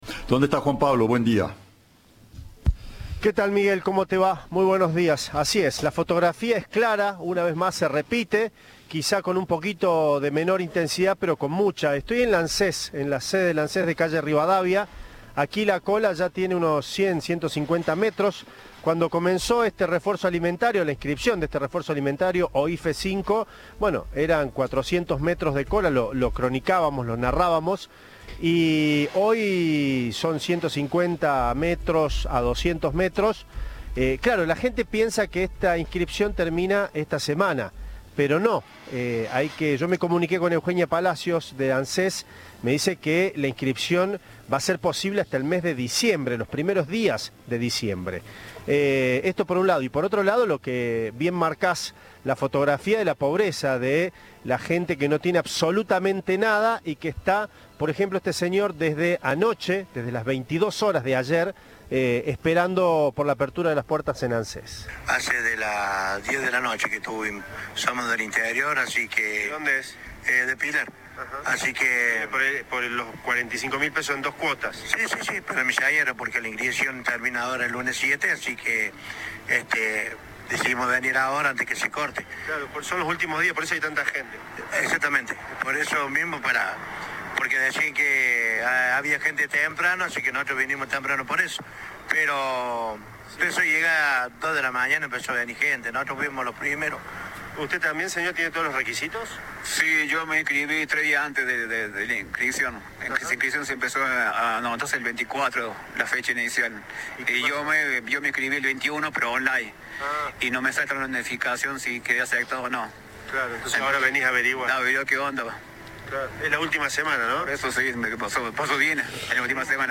"Desde las 10 de la noche que estamos, somos del interior, de Pilar y decidimos venir ahora antes que se corte. Decían que había gente desde temprano, y vinimos temprano por eso, pero a las 2 de la mañana empezó a llegar gente", contó un hombre.
Informe